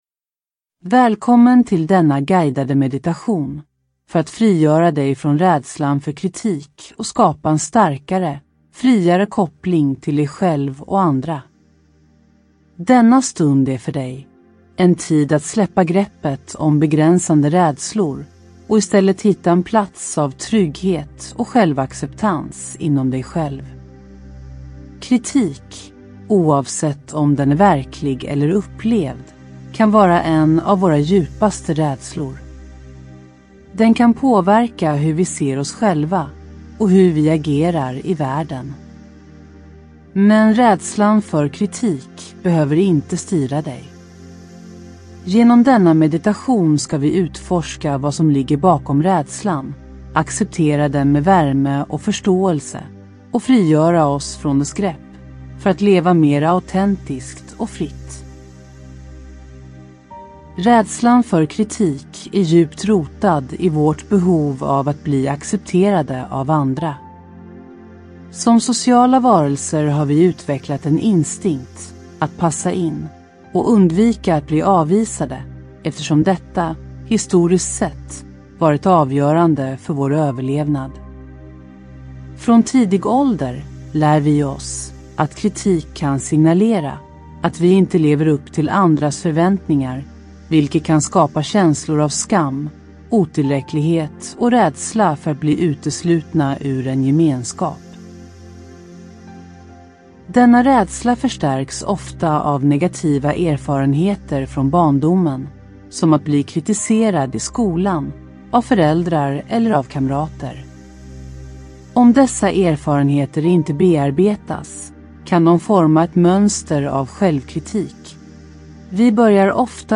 Ljudbok
Den här guidade meditationen är din vägledning till att förstå och släppa taget om den rädslan.